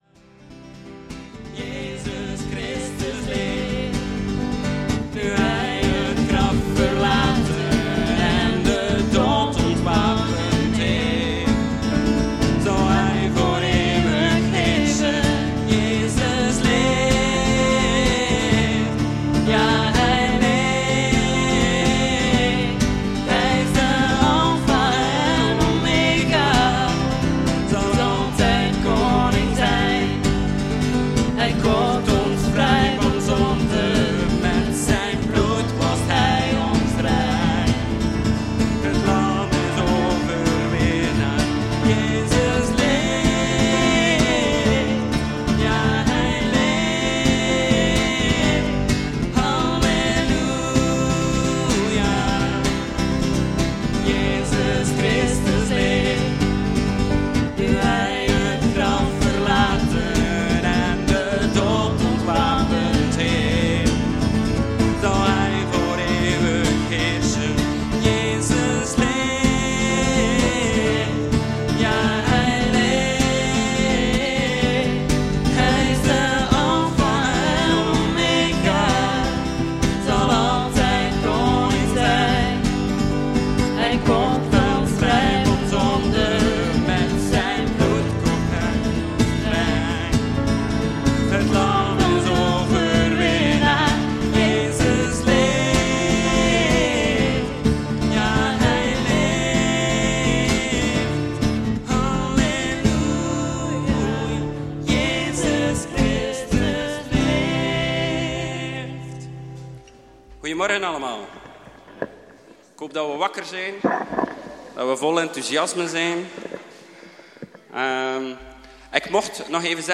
” Aantekeningen bij de preek Inleiding : Waar zoek jij leven ?